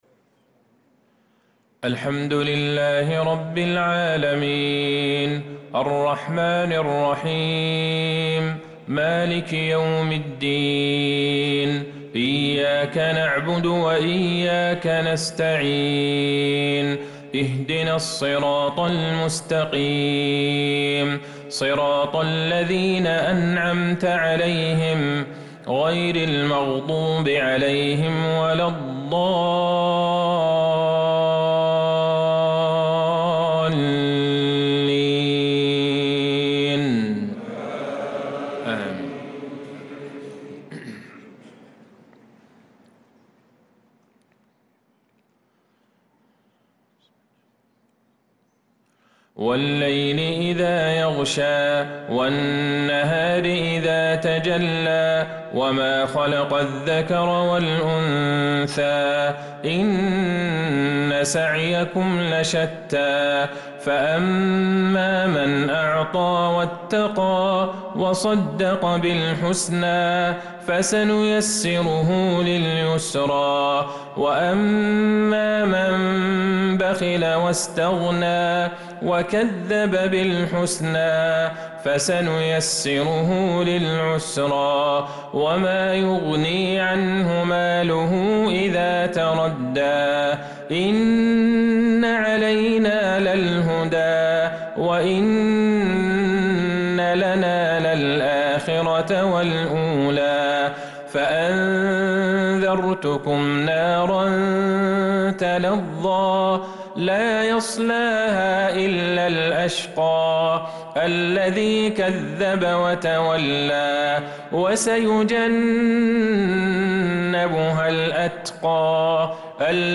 صلاة العشاء للقارئ عبدالله البعيجان 24 ذو القعدة 1445 هـ
تِلَاوَات الْحَرَمَيْن .